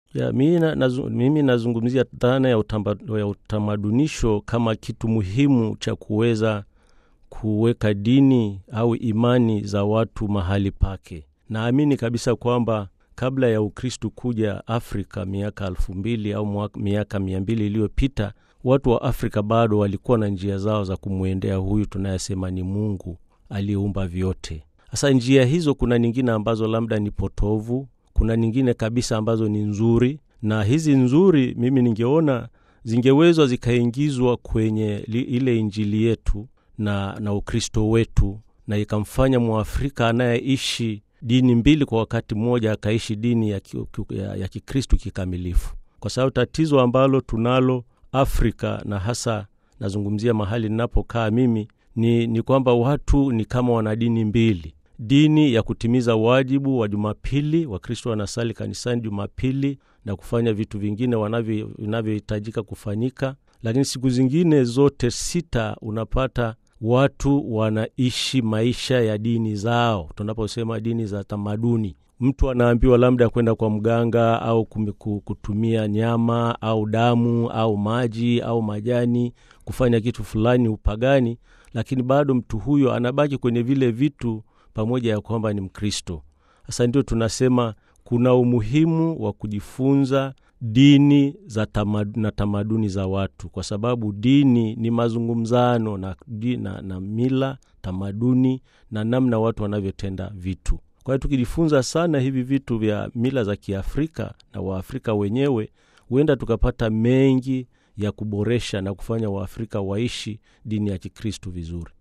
Askofu Augustine Ndeliakyama Shao wa Jimbo Katoliki Zanzibar katika mahojiano na Idhaa ya Kiswahili ya Radio Vatican anasema utamadunisho ndani ya Kanisa Barani Afrika ni dhana au mchakato unaoweza kuwasaidia waamini kuweka misimamo ya maisha ya kidini na kiimani katika uhalisia wake; kwa kuchukua tunu njema, mila, desturi na tamaduni nzuri toka katika maisha ya kiafrika na kuzimwilisha kadiri ya kweli za Kiinjili.